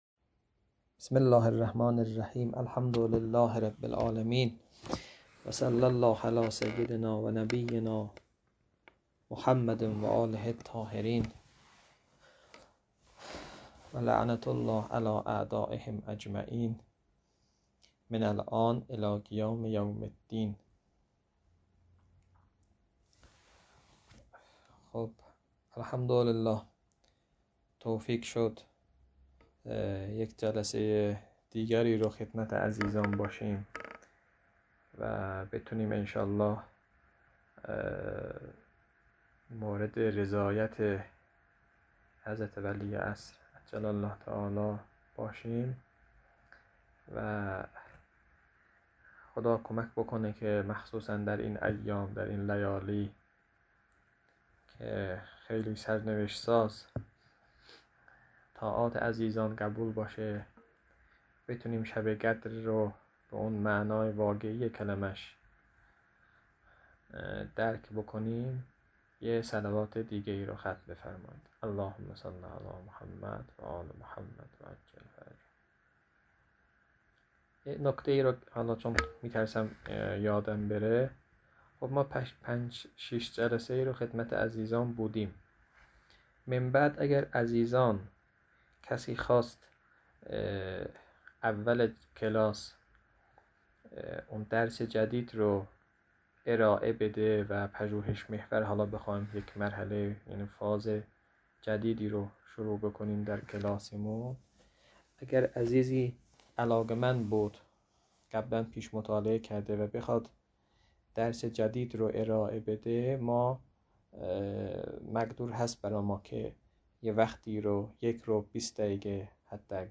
در این بخش، فایل های مربوط به تدریس كتاب حلقه ثانیه متعلق به شهید صدر رحمه الله